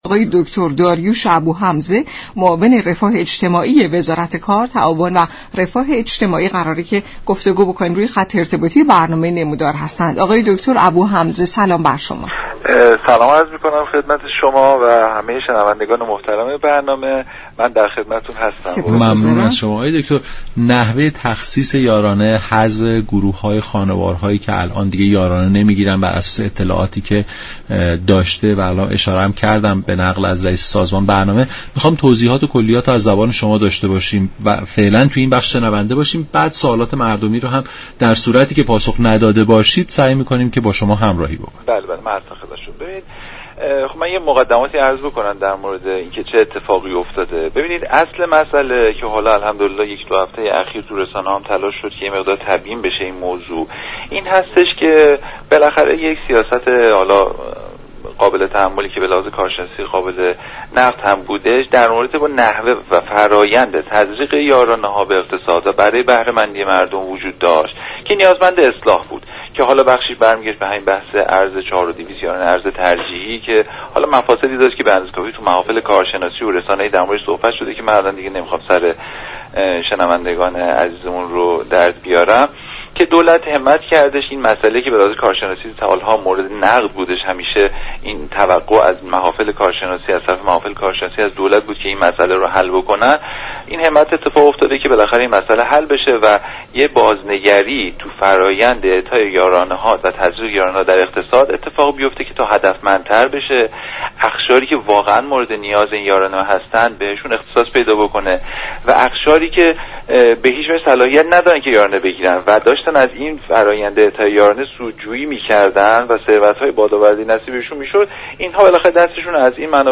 به گزارش شبكه رادیویی ایران، داریوش ابوحمزه معاون كار، تعاون و رفاه اجتماعی در برنامه «نمودار» رادیو ایران به جزئیات بیشتر این خبر پرداخت و گفت: در سال های اخیر بسیاری از كارشناسان نسبت به روند پرداخت یارانه انتقادهایی داشته اند دولت سیزدهم در طرح اصلاح یارانه ها می خواهد پرداختی ها به كسانی تعلق گیرد كه واقعا نیازمند آن هستند.